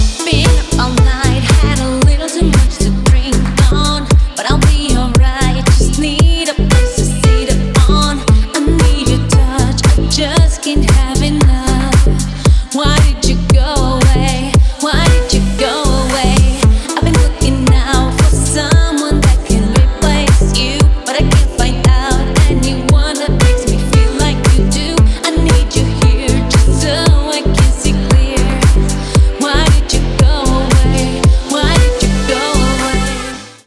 deep house
Electronic
спокойные
красивый женский голос
размеренные
Стиль: deep house